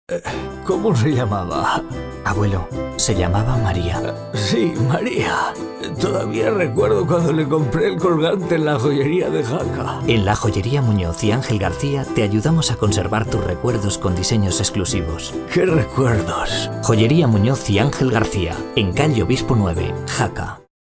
Tonos medios, jóvenes y brillantes. Tono medio de hombre.
Sprechprobe: Werbung (Muttersprache):
Middle tone of man..